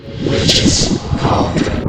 get_cauldron.ogg